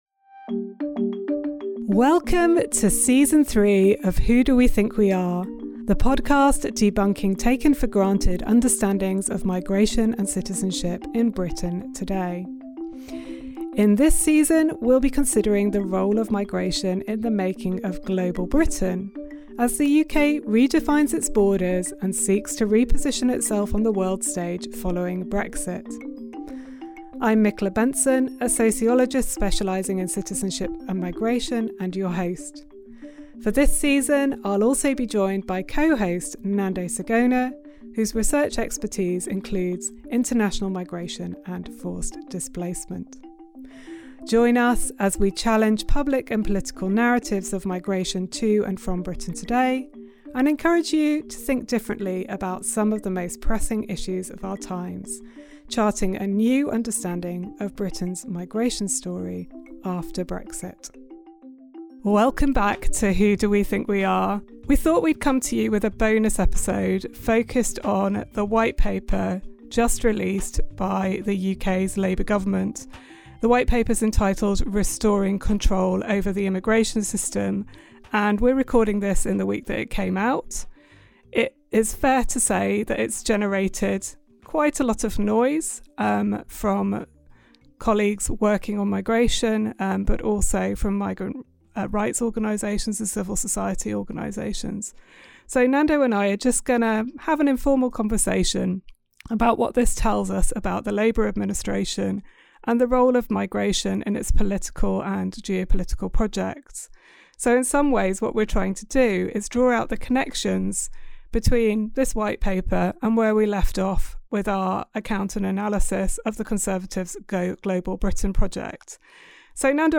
They reflect on several key elements of the paper: health and social care visas, shifts in qualification levels and income requirements, student visas and e-visas. As their conversation highlights, the new immigration plan embeds further the criminalisation of certain migrants—and seeks new ways to do so